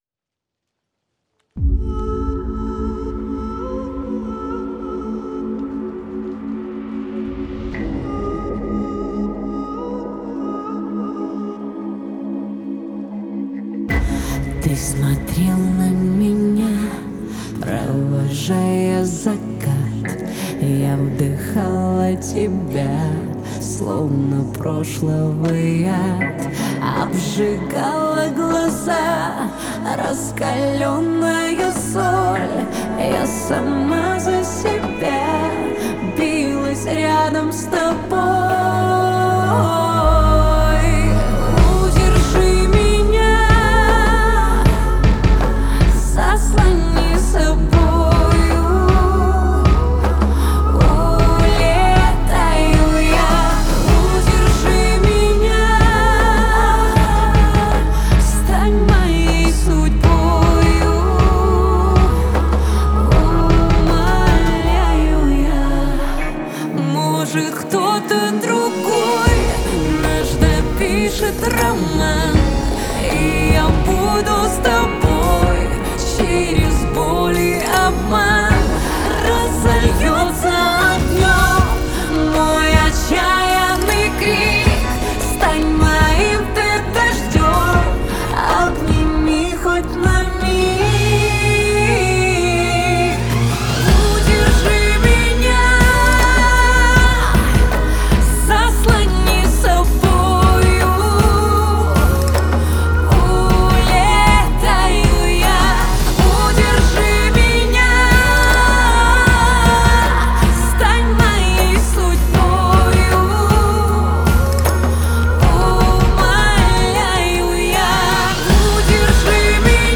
это эмоциональный поп-трек с элементами электронной музыки.
мощный вокал и запоминающуюся мелодию